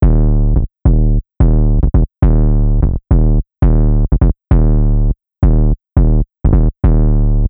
Кручу саб бас ..